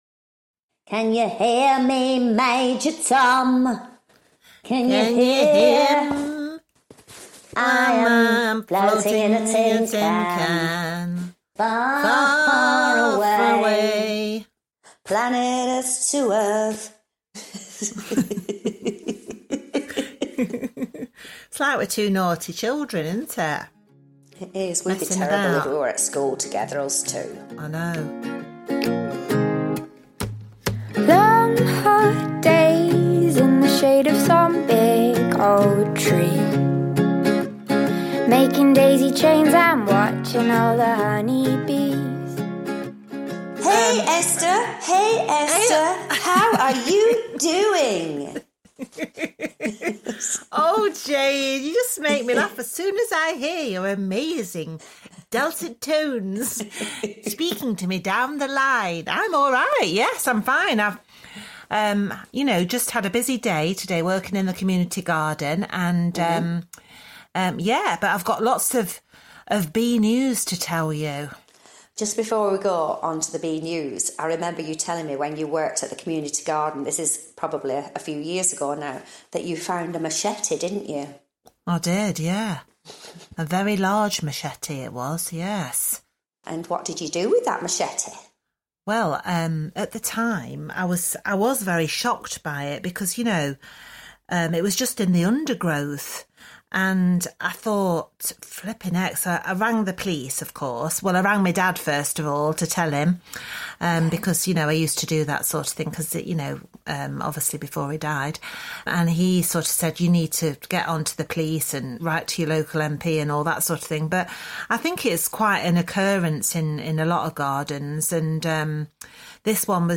As ever, honest stories about the highs and lows of beekeeping and plenty of giggles along the way.